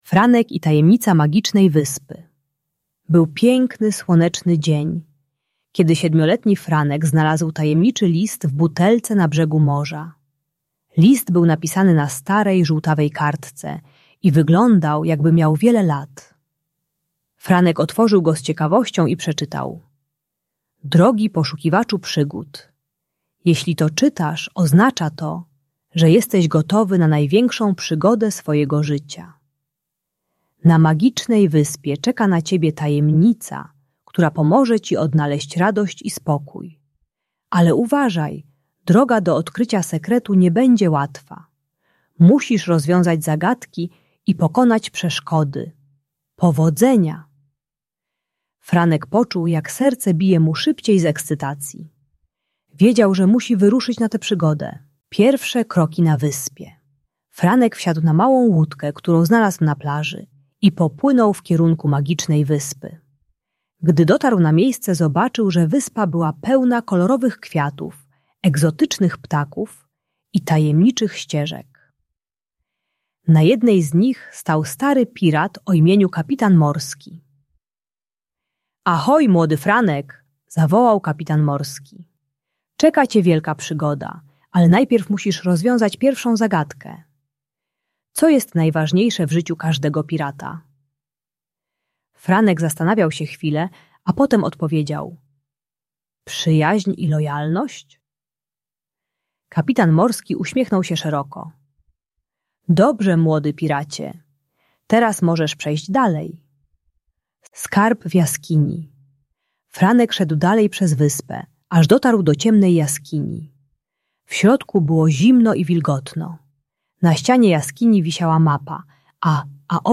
Audiobook dla dzieci o rozwodzie rodziców.